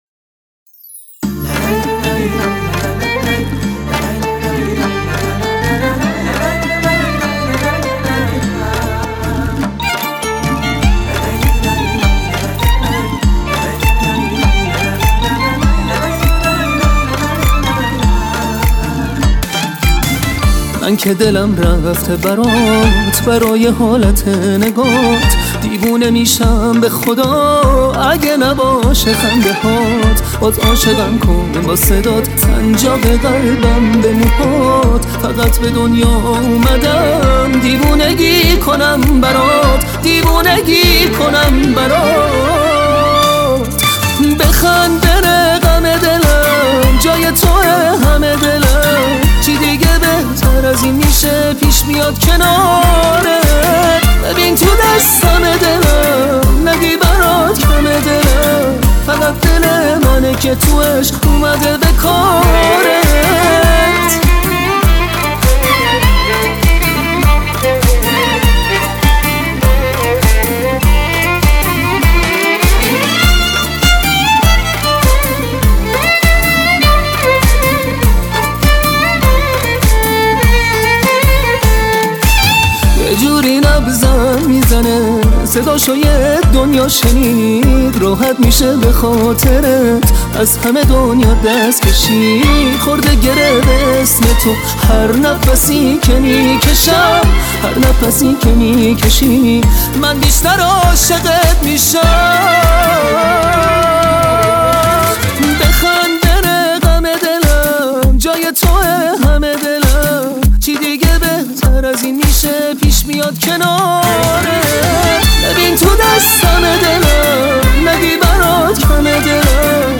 اهنگ جدید و شاد